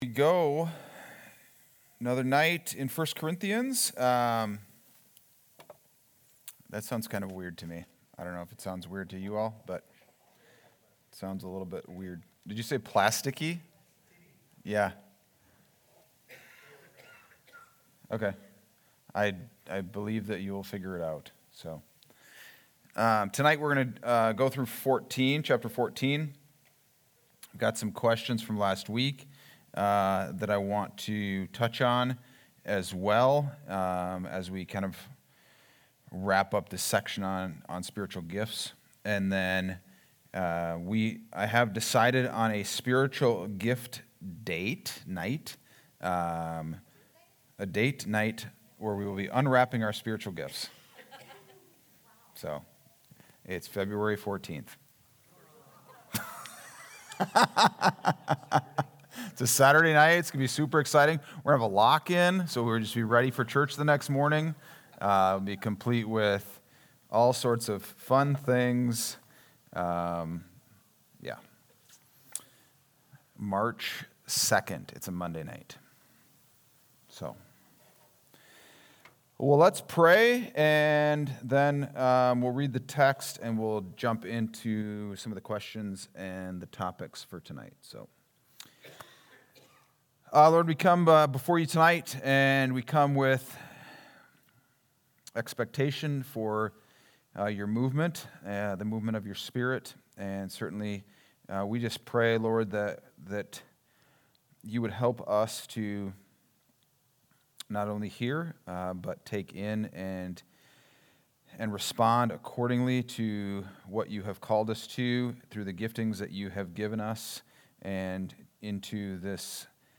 Wednesday Adult Study: 1-28-26